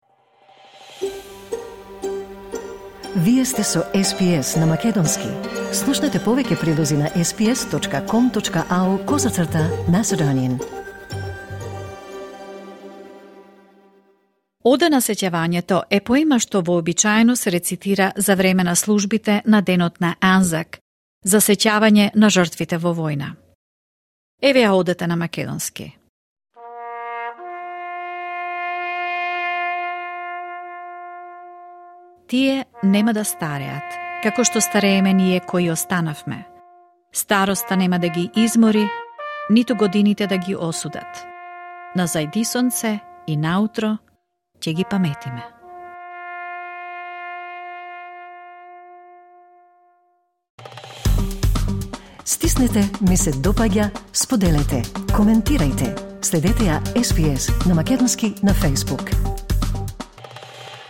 Anzac - Ode of Remembrance in Macedonian "Одата на сеќавањето" е поема што вообичаено се рецитира на службите за Денот на Анзак, за да се одбележат воените жртви. Во соработка со Австралискиот воен споменик, SBS сними преводи на Одат на сеќавањето на 45 јазици.